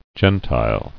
[gen·tile]